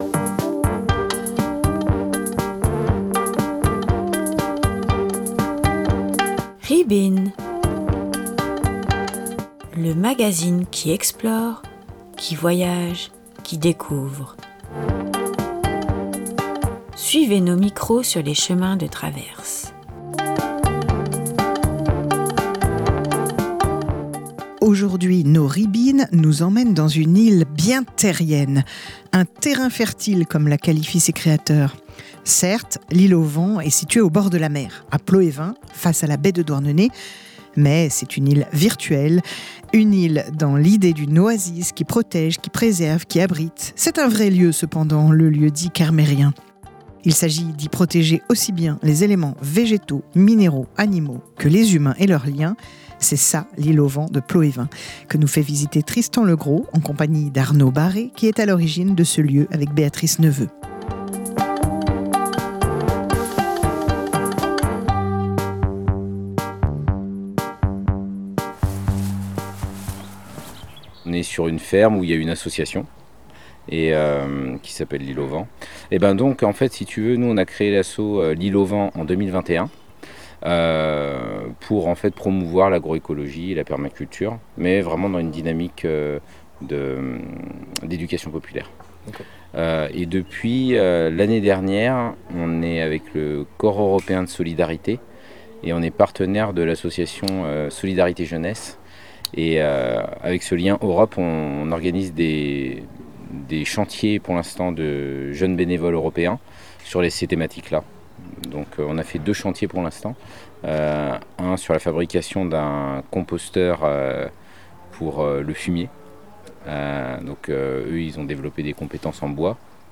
reportages et portraits